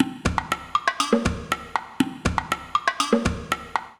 120_perc_2.wav